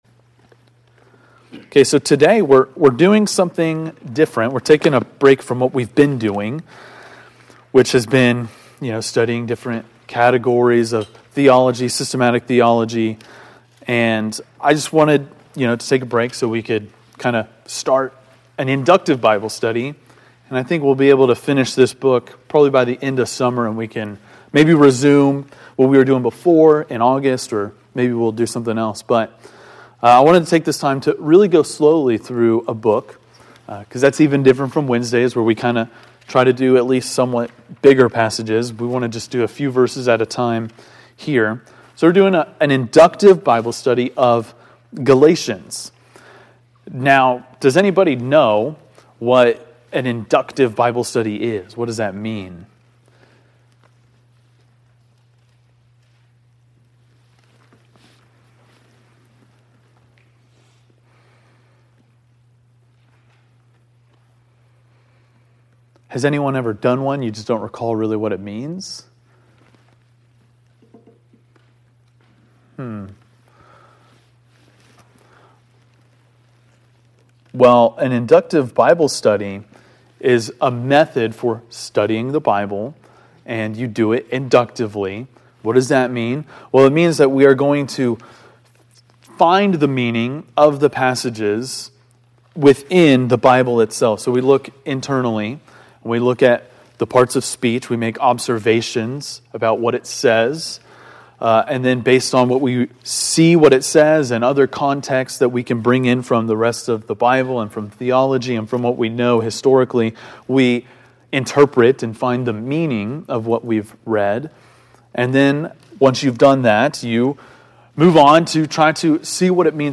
Galatians 1:1-5 (Inductive Bible Study)